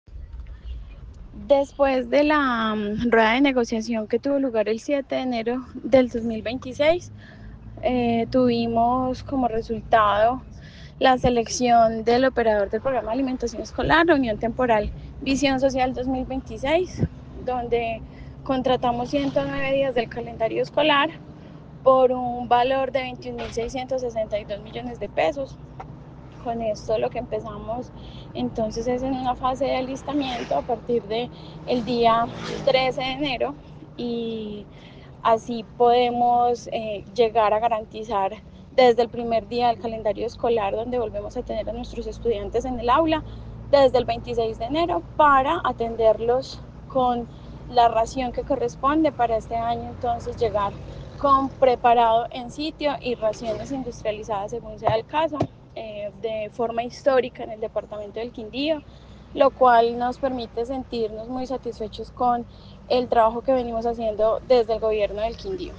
Tatiana Hernández, secretaria de educación del Quindío